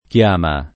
chiama [ k L# ma ] s. f.